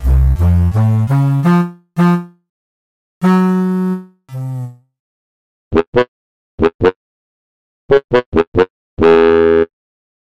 A short demo of instruments from the PLG150-VL.